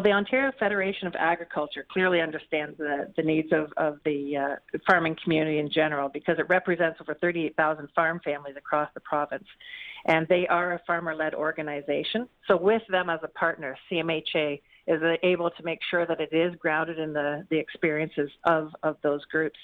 clip-1-mental-health-farmers-interview.wav